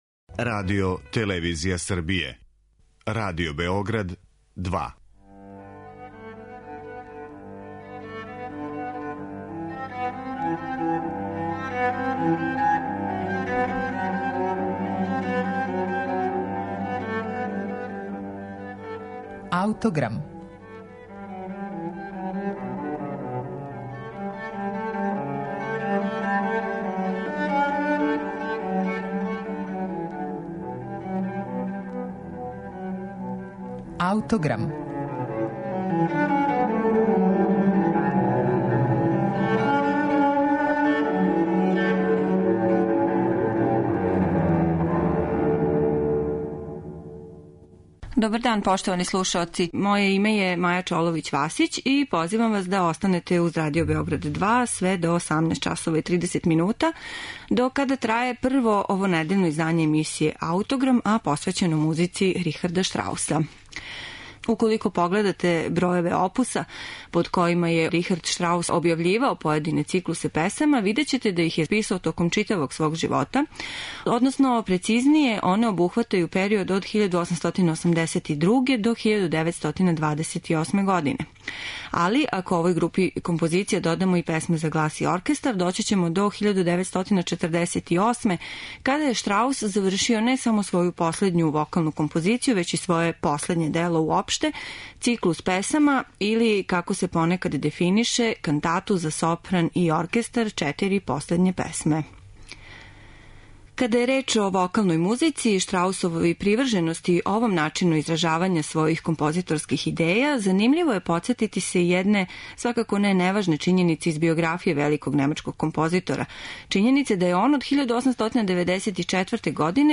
Године 1948. немачки композитор Рихард Штраус завршио је своју последњу вокалну композицију и своје последње дело уопште - циклус песама или, како се понекад дефинише, кантату за сопран и оркестар - Четири последње песме.